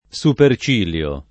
Super©&lLo] s. m.; pl. -li (raro, alla lat., -lii) — latinismo antiq. per sopracciglio nel sign. proprio; tuttora dell’uso lett. nel sign. fig. di «cipiglio altezzoso» (con l’agg. der. supercilioso [Super©ilL1So])